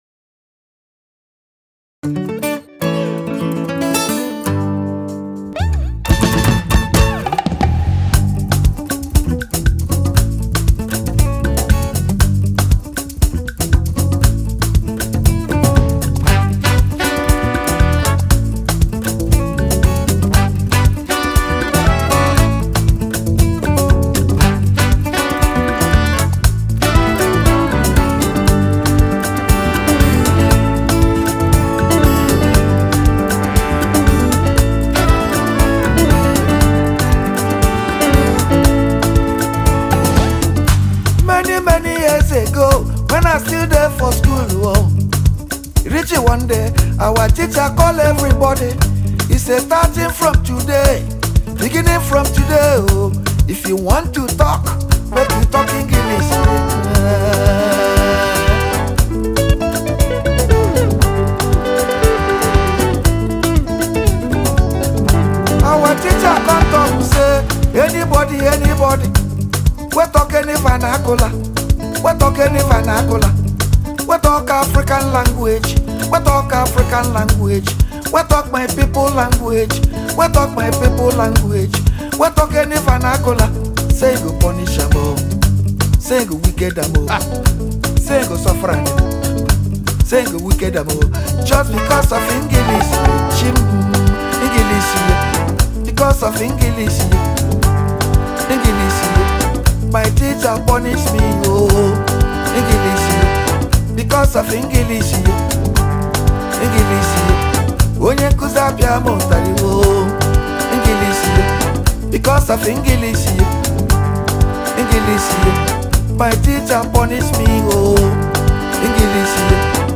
Nigerian Highlife
singer and songwriter